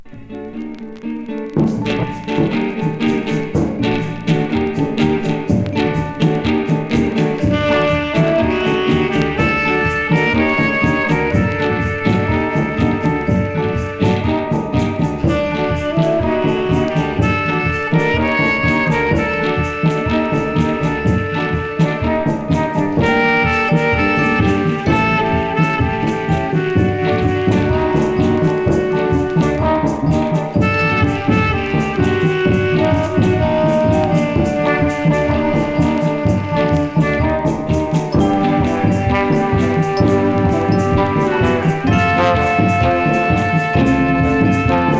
Jazz, R&B　France　12inchレコード　33rpm　Mono